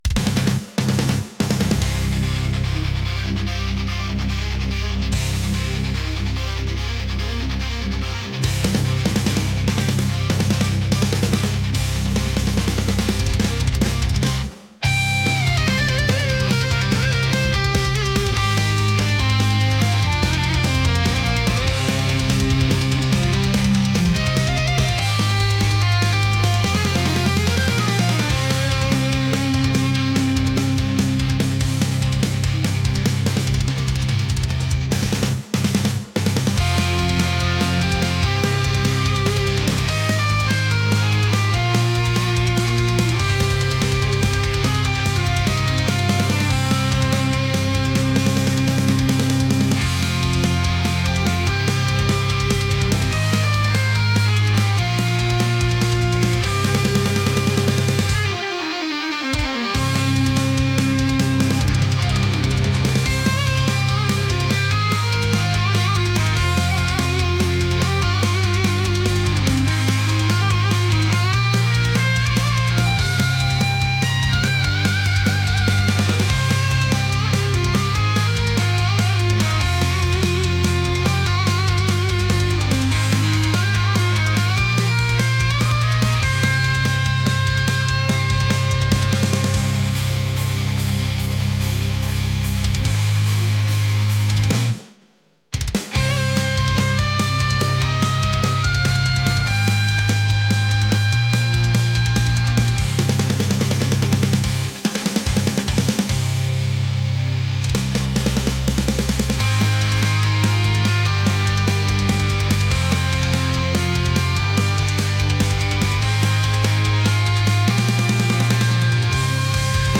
intense | aggressive | heavy | metal